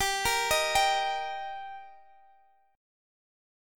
Gm#5 Chord
Listen to Gm#5 strummed